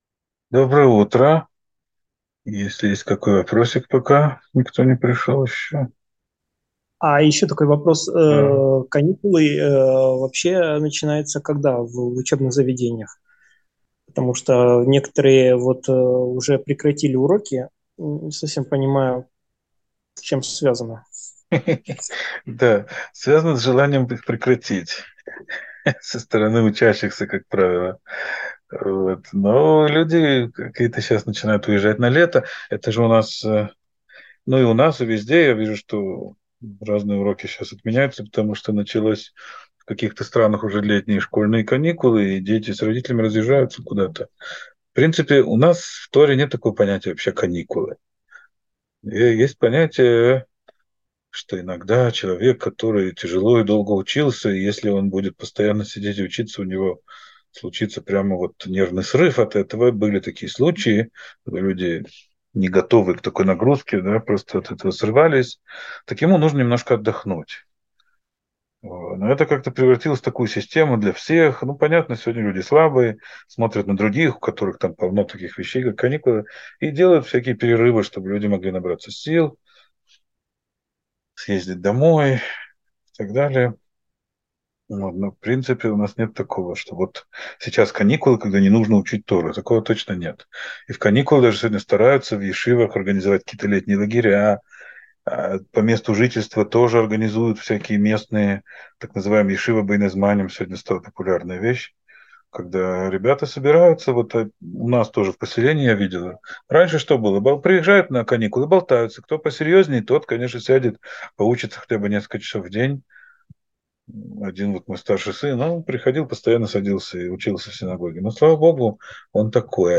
Урок 102.